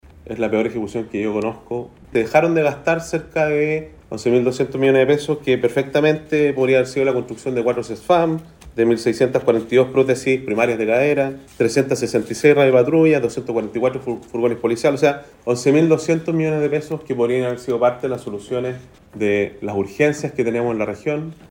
Otro aspecto que reveló el análisis presupuestario es la baja ejecución, que llegó al 89,5%, una cifra nunca vista, dijo el gobernador Giacaman.